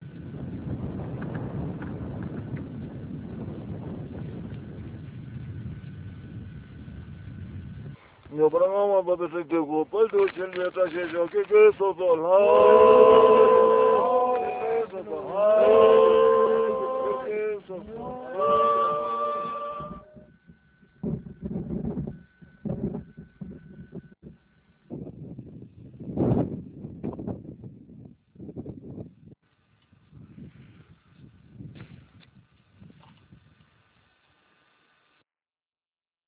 Gebetszeremonie
Kurzer Videoclip von der Zeremonie
gebetszeremonie.ram